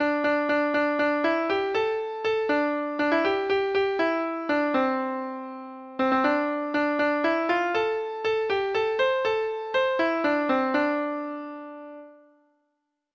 Traditional Trad. Young Alan (4) Treble Clef Instrument version
Folk Songs from 'Digital Tradition' Letter Y Young Alan (4)
Traditional Music of unknown author.